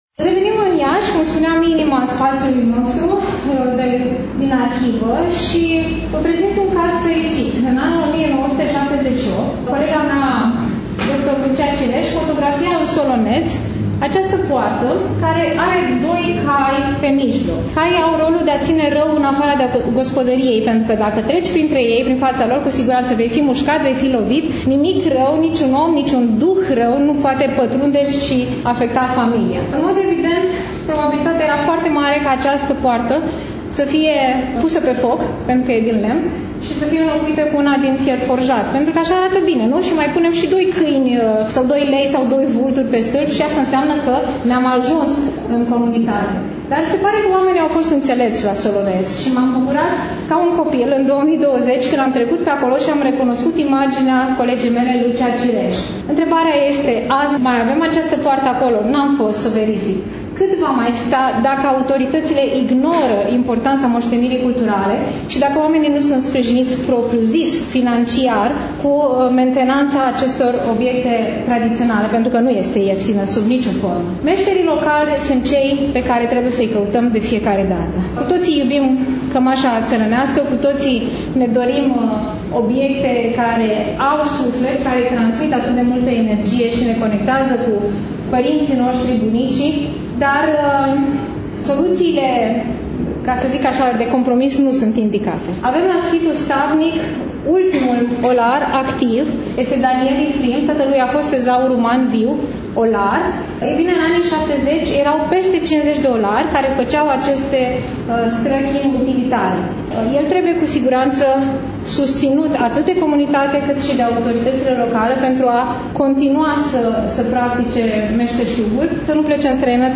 Astăzi, după cum bine știți, relatăm de la expoziţia de carte „Satul românesc”, manifestare culturală desfășurată, nu demult, la Iași, în incinta Bibliotecii Centrale Universitare „Mihai Eminescu”.